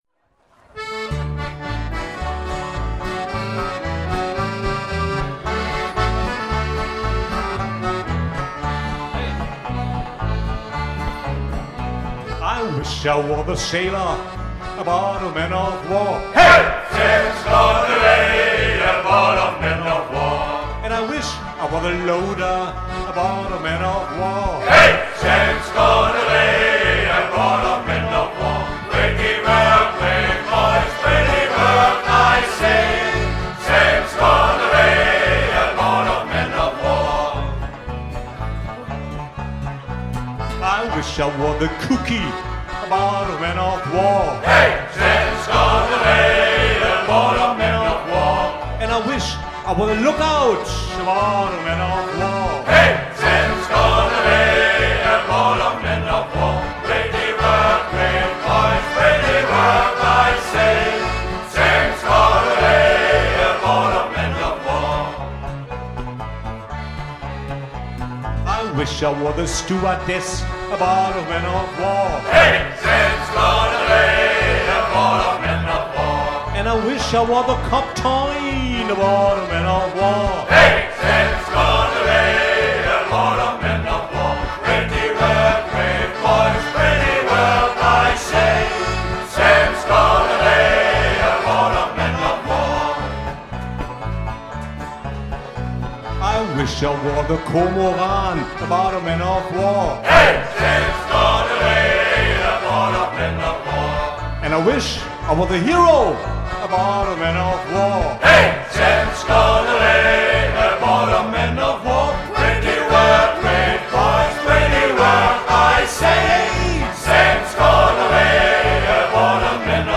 Shanty-Chor der Marinekameradschaft